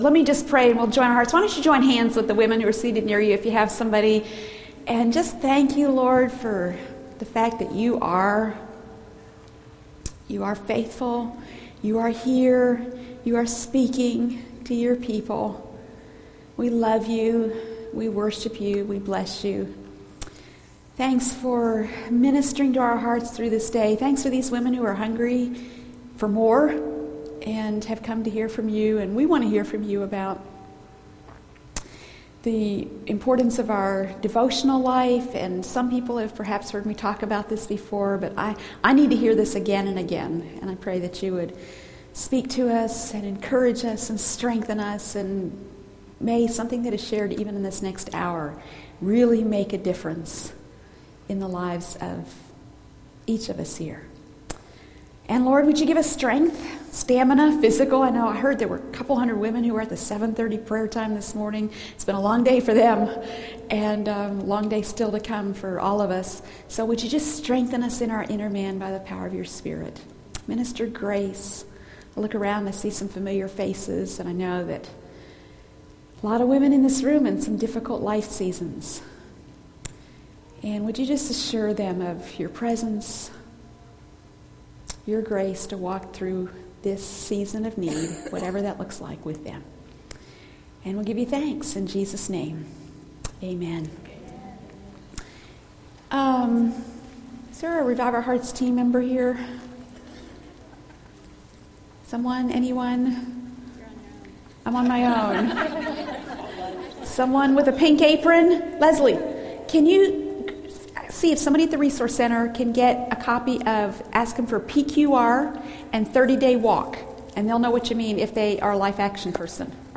| True Woman '10 Fort Worth | Events | Revive Our Hearts
This message may change your whole perspective on daily devotions! Discover why a consistent devotional life is foundational to cultivating an intimate, fruitful relationship with God.